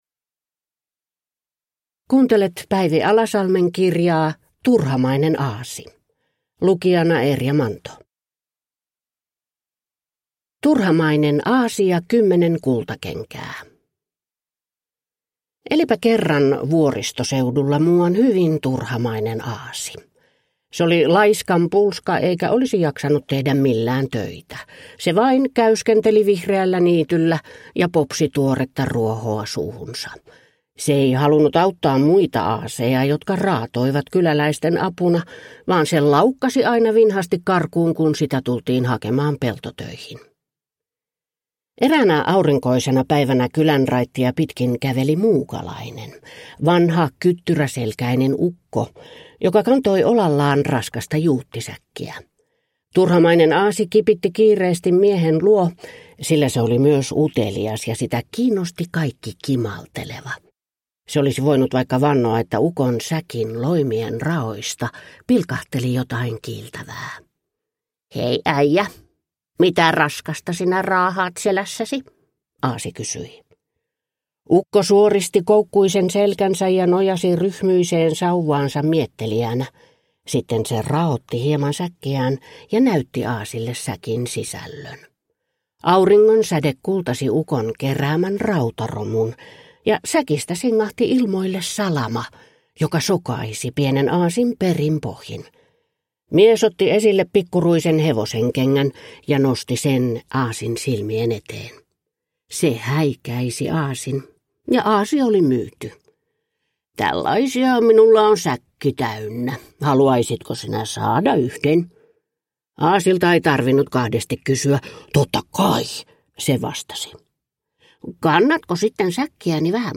Turhamainen aasi (ljudbok) av Päivi Alasalmi | Bokon